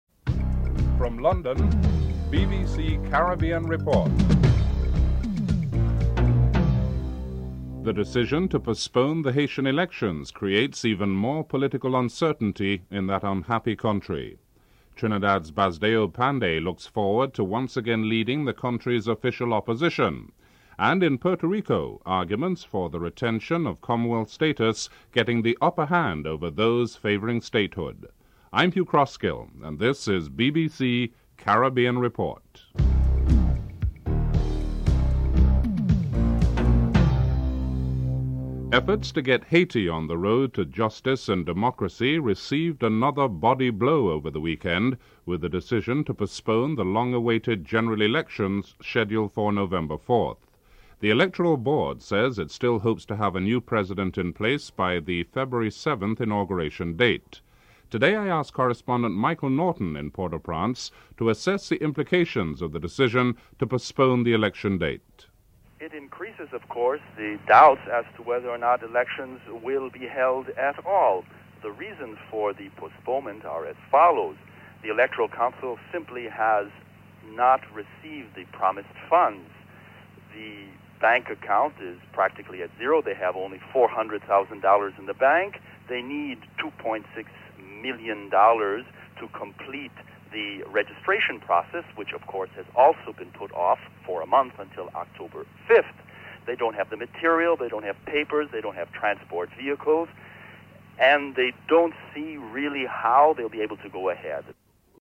dc.contributor.authorPanday, Basdeo (interviewee)
dc.formatStereo 192 bit rate MP3;44,100 Mega bits;16 biten_US
dc.typeRecording, oralen_US